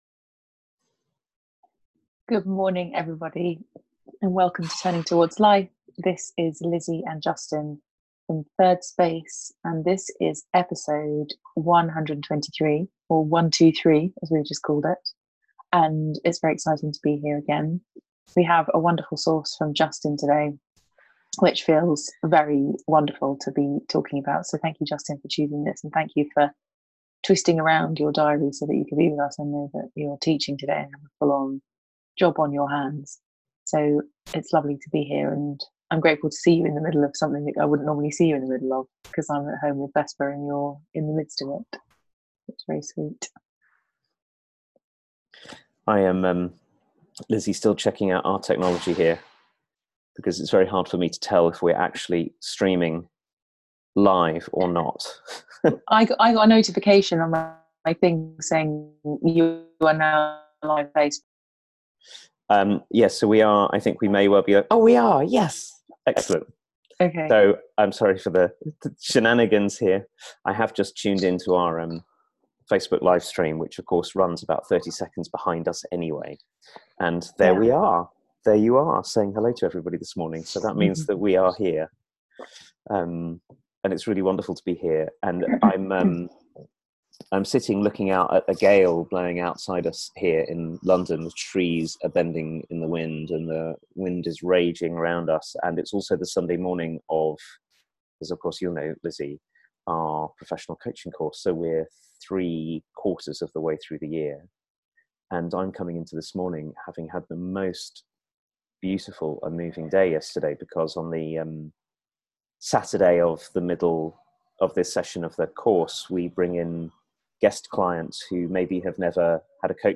And what creativity and possibility open when we give up trying to control one another so that instead we can meet one another fully? A conversation about the gifts of finding 'the other' in ourselves, and ourselves in the other